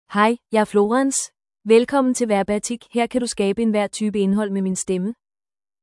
FemaleDanish (Denmark)
Florence — Female Danish AI voice
Voice sample
Female